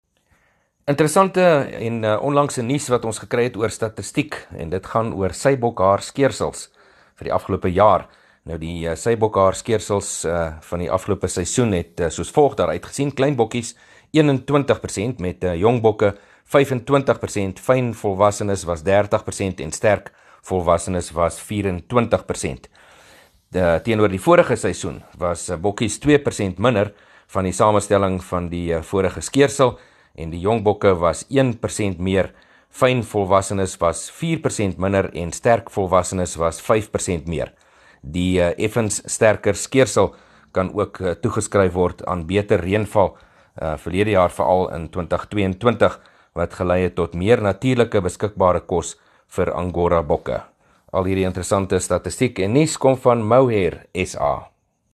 3 Apr PM berig oor die onlangse statistieke oor sybokhaarskeersels